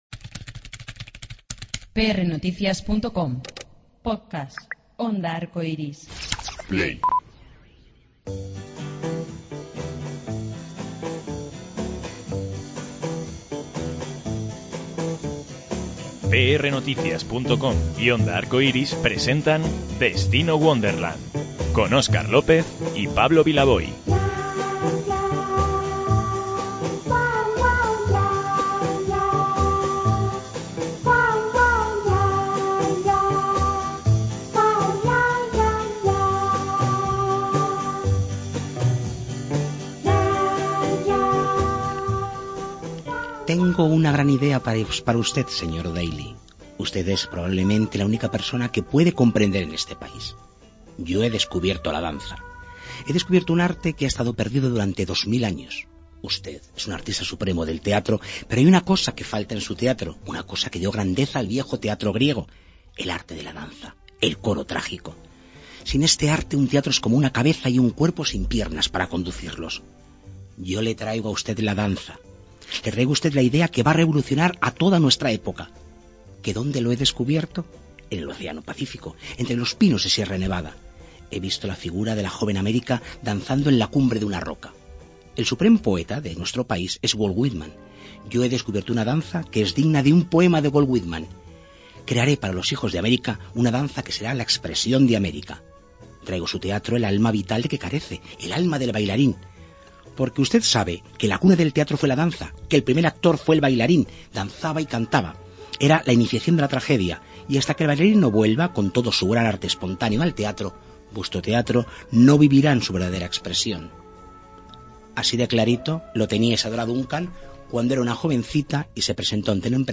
Silvia Marsó es una de nuestras actrices más carismáticas y queridas. Hoy, desde su camerino, nos presenta su nueva obra La puerta de al lado.